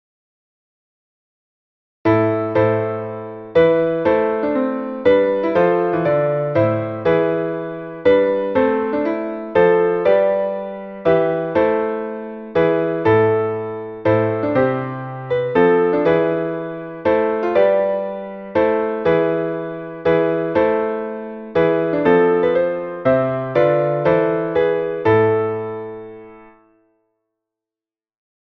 Meter: 8.8.8.8
Key: a minor